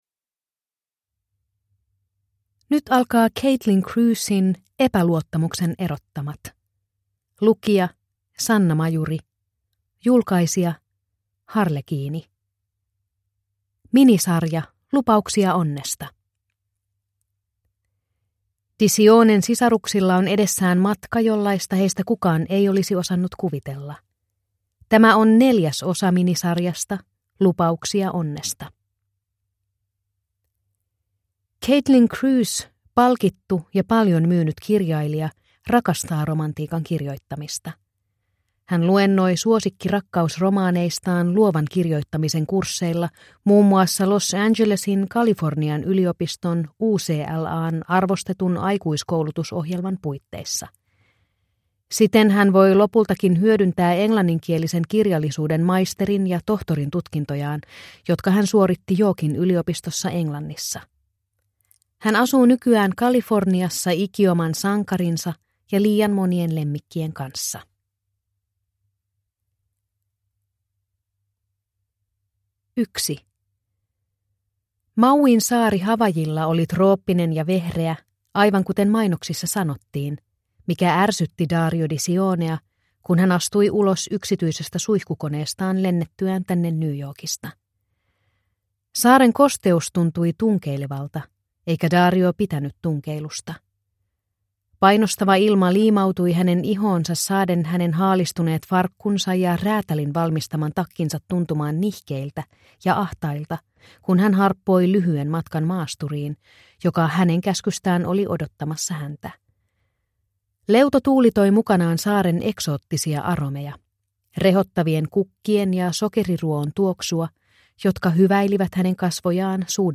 Epäluottamuksen erottamattomat (ljudbok) av Caitlin Crews